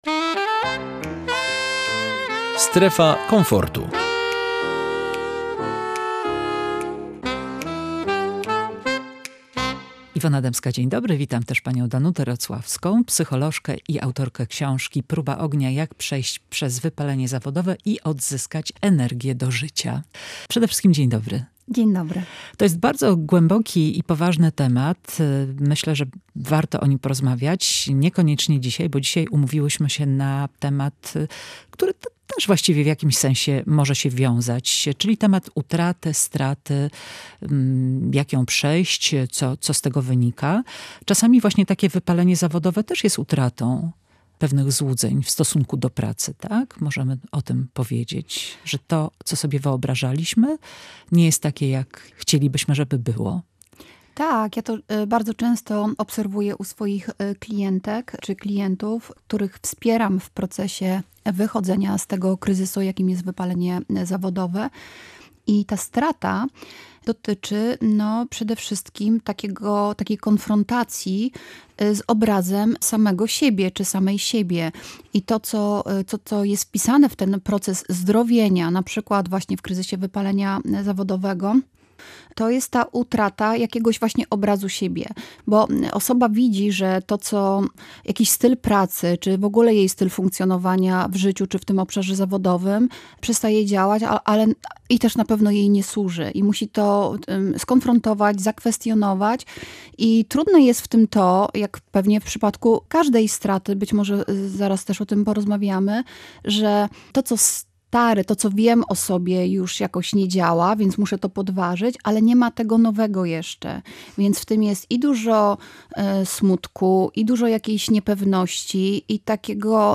Strata nie musi być końcem. Rozmowa o szansach i nowych początkach